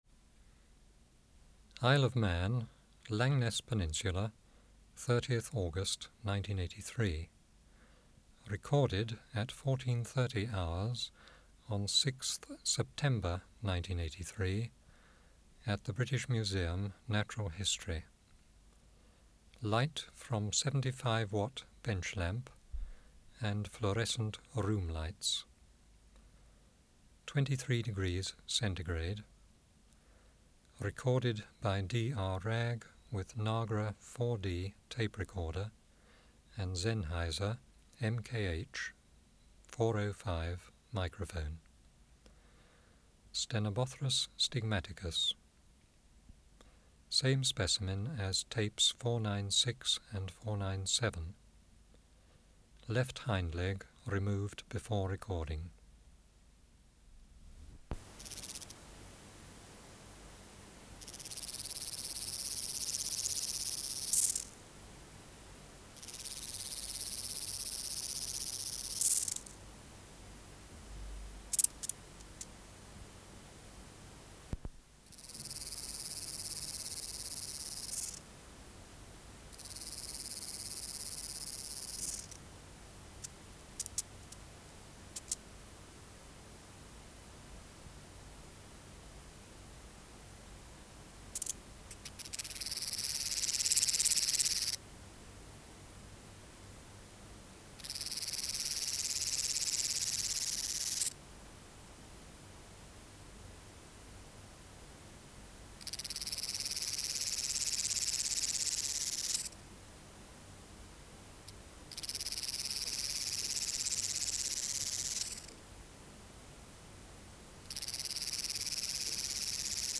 Recording Location: BMNH Acoustic Laboratory
Reference Signal: 1 kHz for 10 s
Substrate/Cage: Small recording cage
Courtship song next to female
Microphone & Power Supply: Sennheiser MKH 405 Distance from Subject (cm): 5 Filter: Low Pass, 24 dB per octave, corner frequency 20 kHz
Tape: BASF SPR50LH Tape Speed (cm/s): 38.00